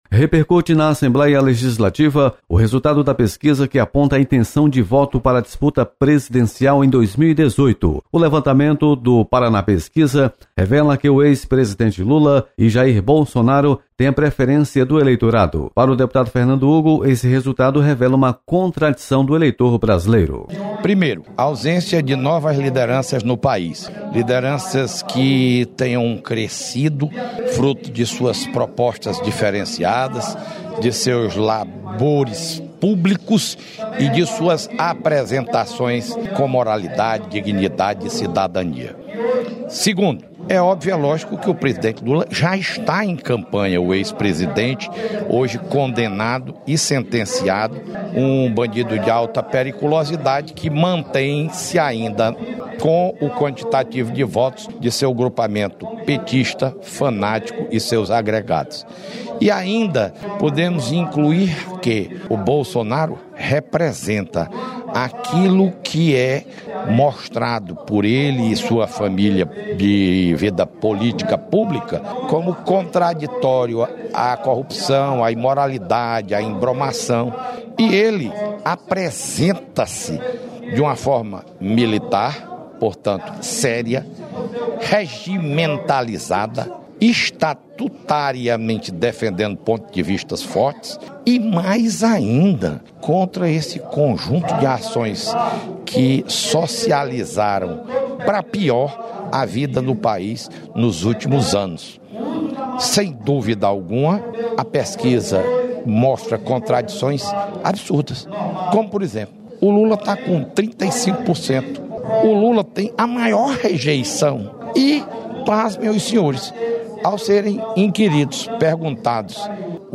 Parlamentares repercutem pesquisa que aponta intenção de voto para eleição presidencial. Repórter